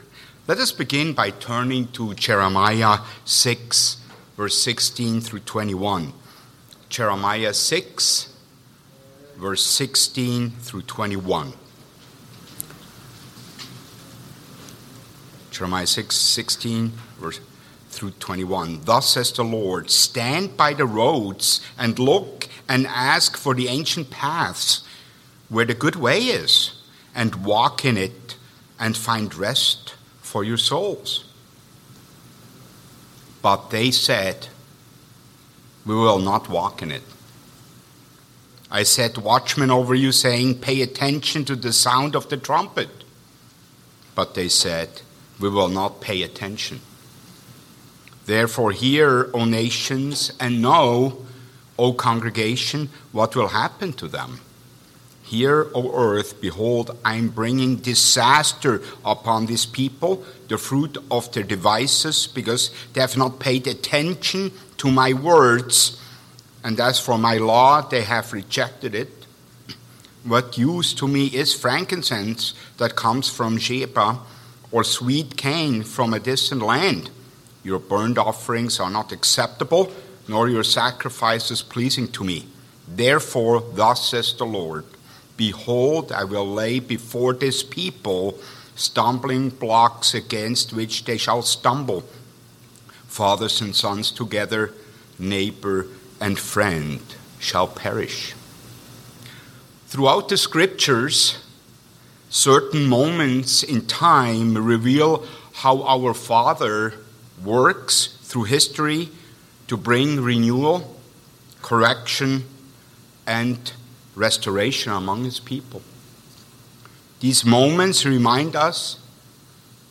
Given in Eau Claire, WI